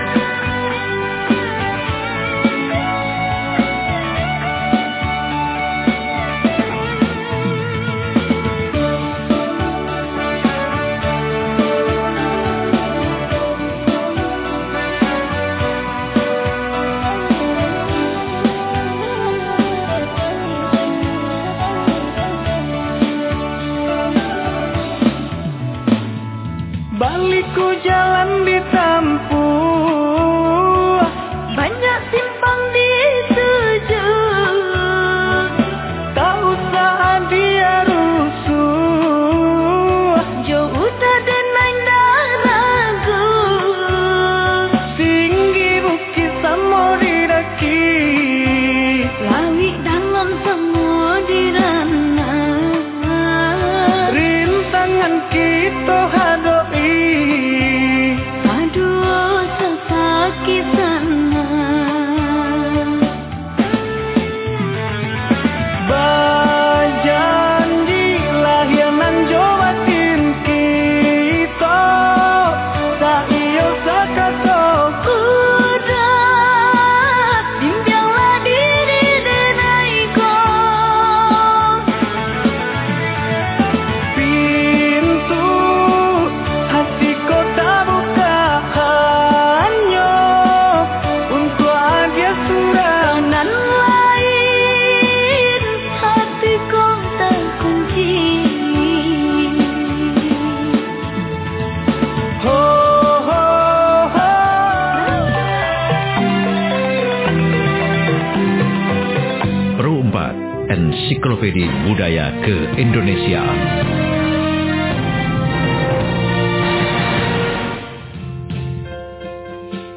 Dialog Interaktif Kearifan Lokal Sumatera Barat Kamis 24 Februari 2022, Pro 4 FM 92,4 Mhz Jam 08.00 WIB LPP RRI Padang, Topiknya